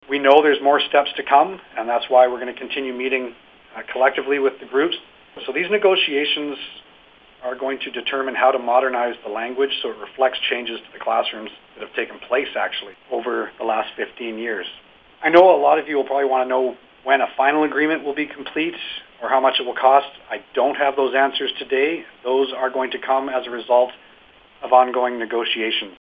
Mike Bernier, Minister of Education says this agreement doesn’t conclude the discussions with the BCTF but is a first step….